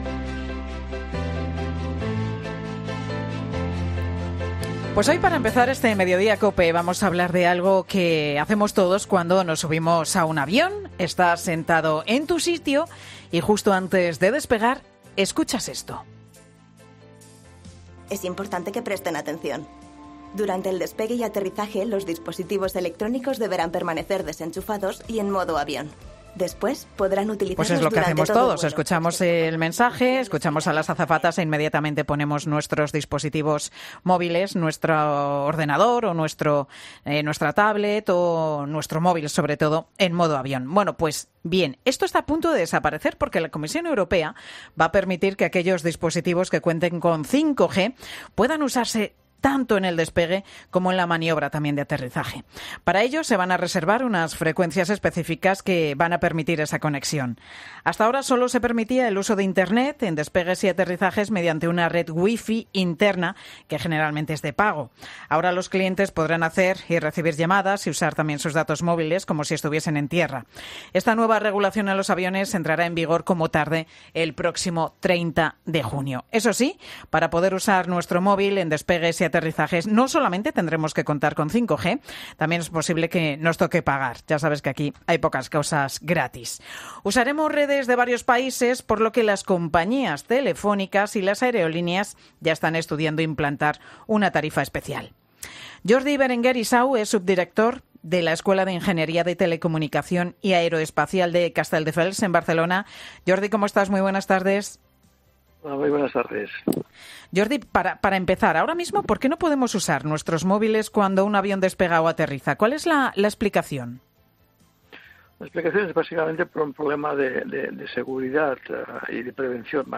Un experto explica cómo podemos conectarnos a internet en el avión: "Se podrá llamar por móvil como en tierra"